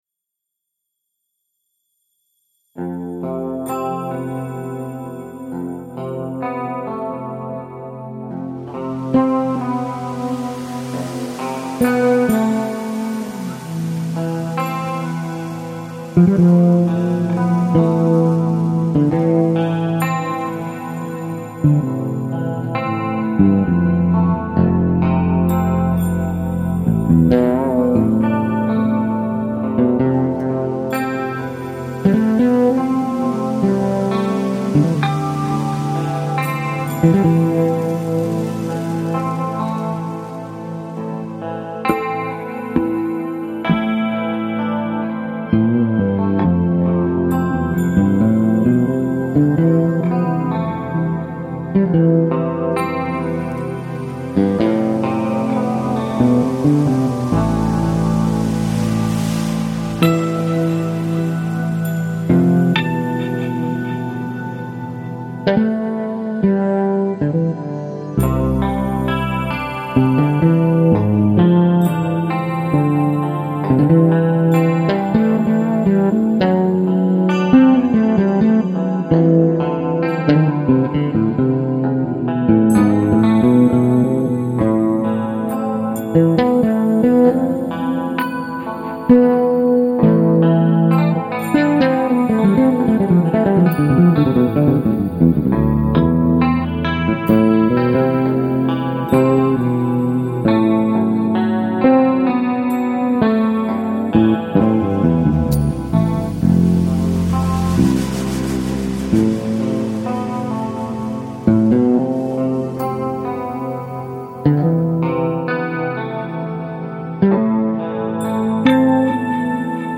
Extensive fretless electric bass library for Kontakt